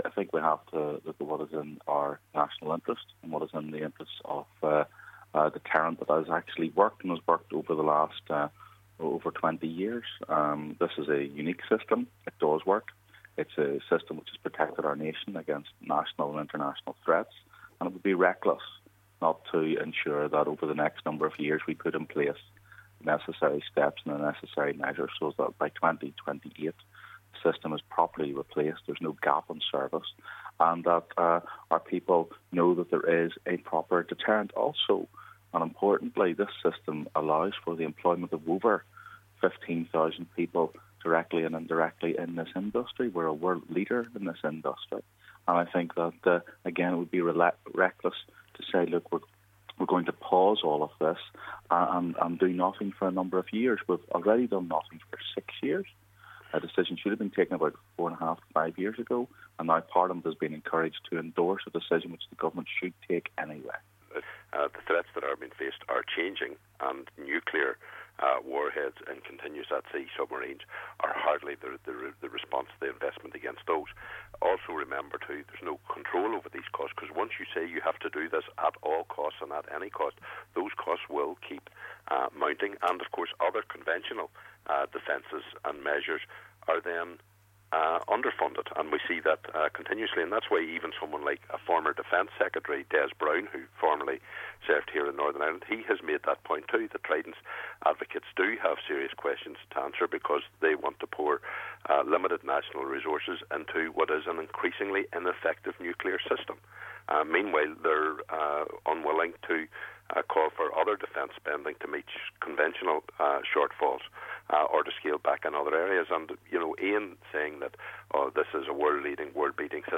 Are you in favour of the UK building more nuclear submarines? MPs Ian Paisley & Mark Durkan debate the issue